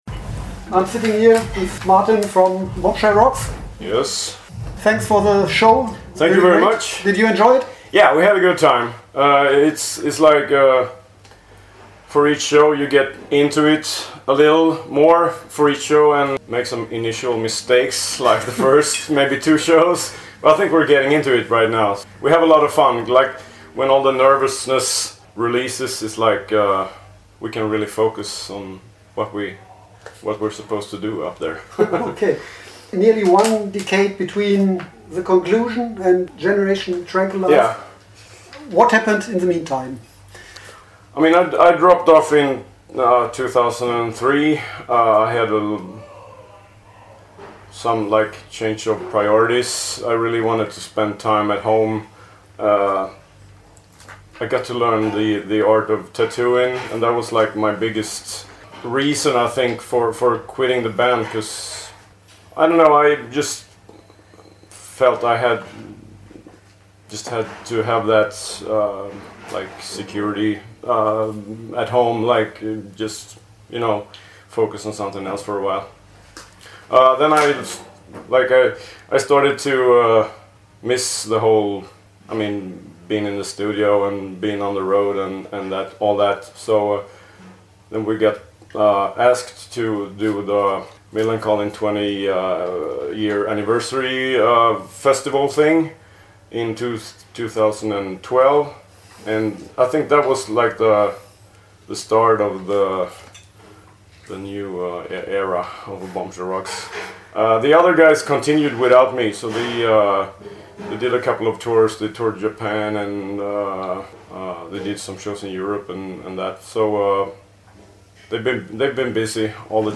Interview
Mai 2015 Nächste Episode download Beschreibung Teilen Abonnieren Am 4.5. haben Bombshell Rocks zusammen mit Millencolin in der Live Music Hall in Köln gespielt.